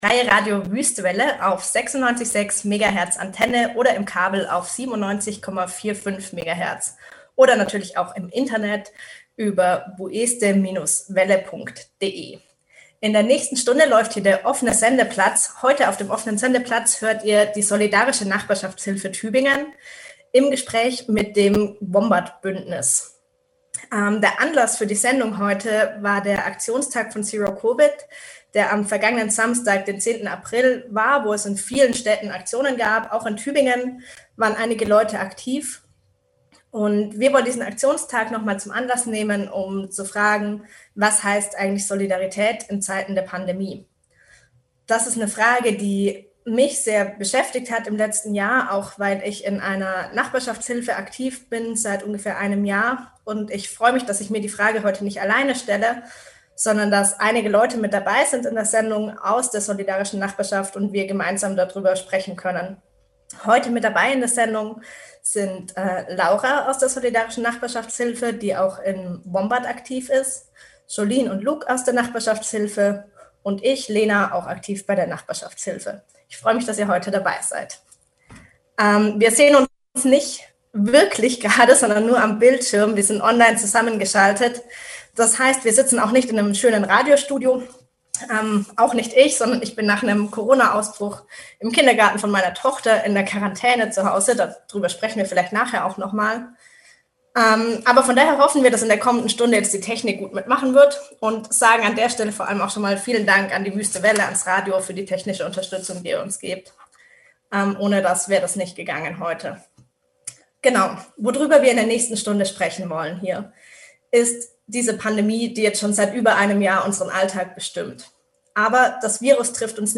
Die solidarische Nachbarschaft Tübingen im Gespräch mit dem Wombat-Bündnis